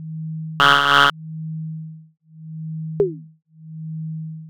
Every character was a cacophony of solenoid strikes, a staccato negotiation between magnetic impulse and physical resistance. Listen to this diagnostic initialization sequence I reconstructed from servo timing diagrams and strike physics:
• 0.00–0.55s: The 9-pin printhead traverses left-to-right in diagnostic mode, each pin firing at its resonant frequency (1240–1480 Hz depending on solenoid position). The slight jitter in timing comes from mechanical variance in the guide rod lubrication—each printer had its own accent
• 0.55–2.80s: Column-by-column strikes forming the implicit test pattern. Each impact generates a complex tone: fundamental frequency plus a 2.273× harmonic from the stamped steel frame resonance
• 2.80–3.10s: The carriage return—a doppler-shifted whirl as the DC motor reverses polarity and the head assembly accelerates back to home position at 40 cm/s. That descending whine is the loaded motor fighting inertia
• Throughout: Stepper motor idle at 158.7 Hz—the chopper driver frequency—creating the subconscious texture of a machine waiting for instructions